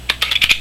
一般打开7时，你会听到吱呀刷拉一响，特别刺耳，在一看那些对焦时前段部分转动的镜头，那前段部分会猛地伸出很多，然后收回去。
听听7 开启是的声音你就知道了，这是接着80-200mm G头时录的：